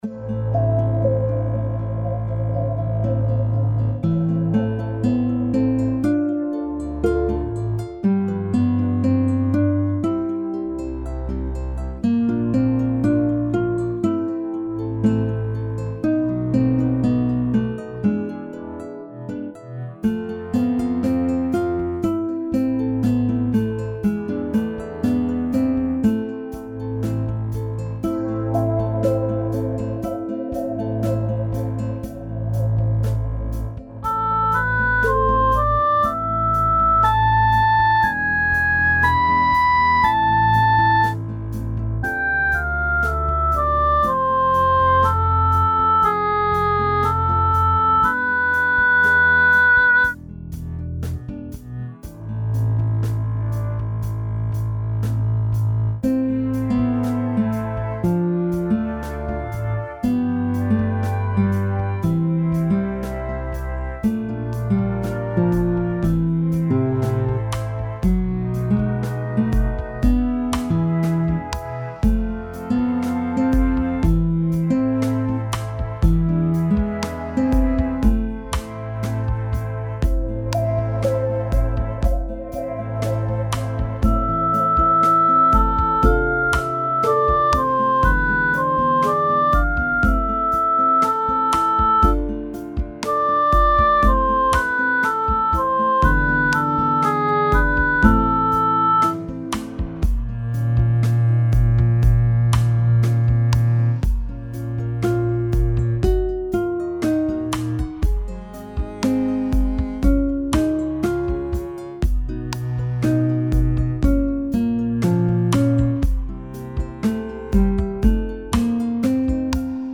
Чилаут-4б.MP3